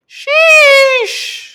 Sheesh (painful)